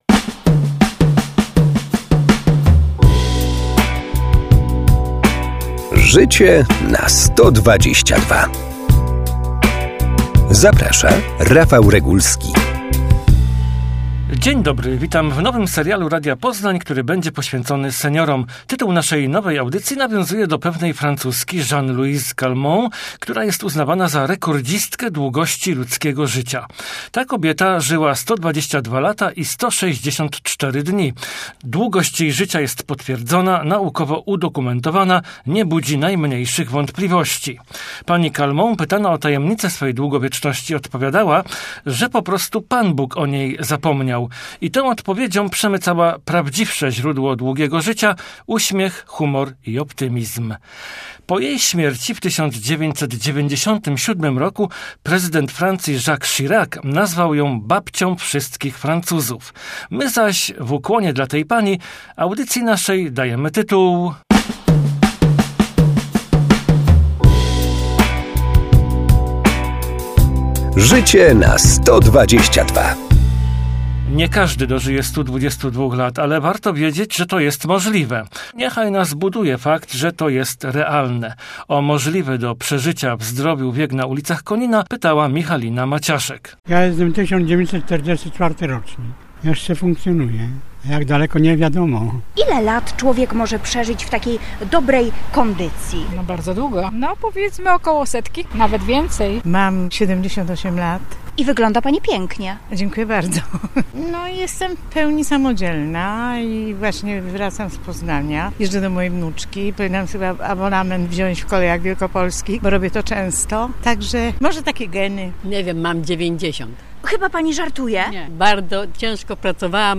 Jak długo może żyć człowiek? W jaki sposób może poprawić jakość swego długiego życia? Występują: lekarz geriatra